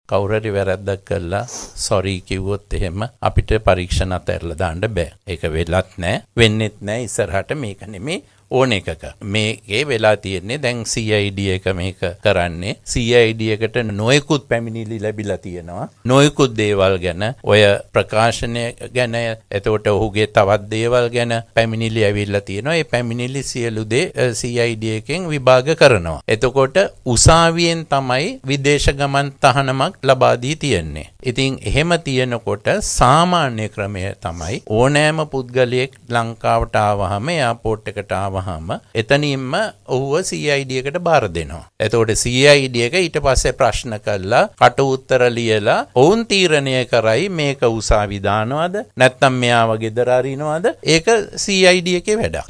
- මහජන ආරක්ෂක අමාත්‍ය ටිරාන් අලස්
ඔහු මේ බව සදහන් කළේ අද පැවති මාධ්‍ය හමුවකදී.